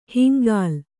♪ hingāl